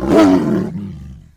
CosmicRageSounds / wav / general / combat / creatures / tiger / she / attack3.wav
duplicated the tiger sounds to make them working for both male and female kinds.